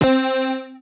Lead.wav